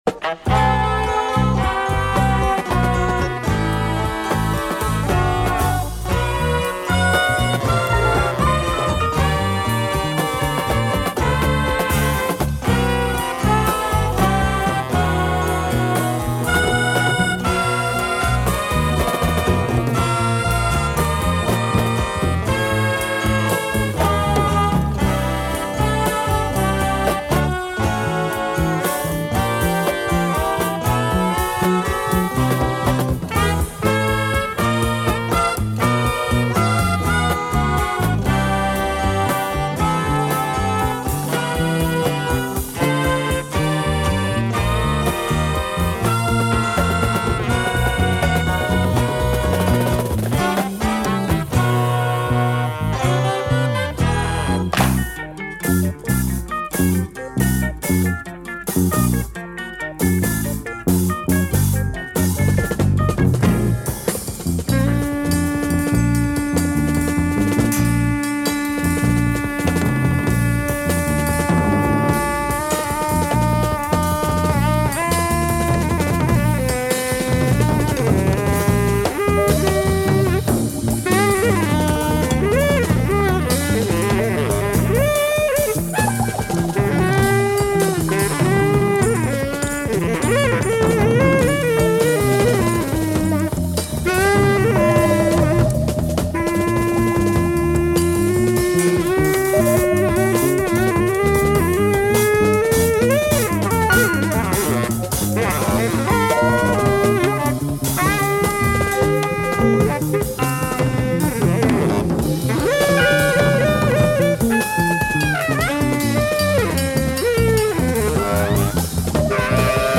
Great British prog jazz / free jazz session